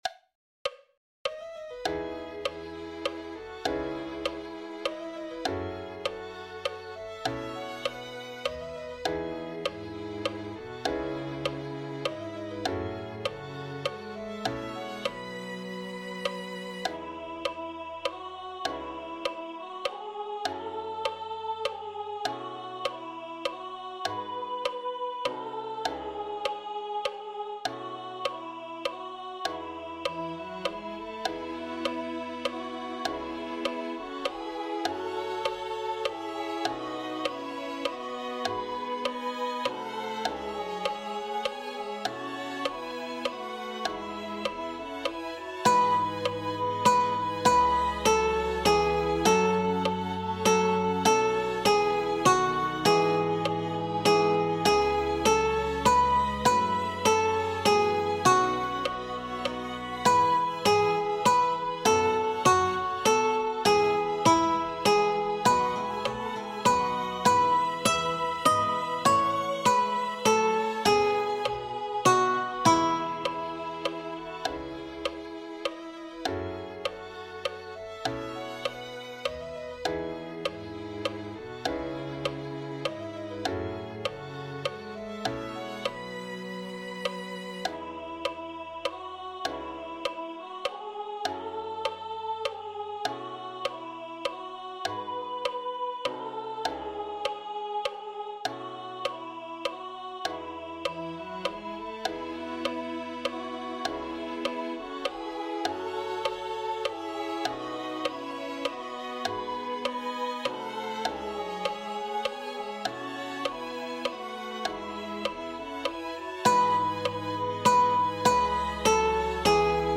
This page contains recordings of the notes for the soprano voice parts for the song that will be presented during the Christmas Sunday service (December 21, 2025).
Note that blank measures for the parts are not skipped; it follows the music as written so if you hear silence that's because there's nothing written for your part in that portion of the recording (i.e., your device isn't broken and your ears still work).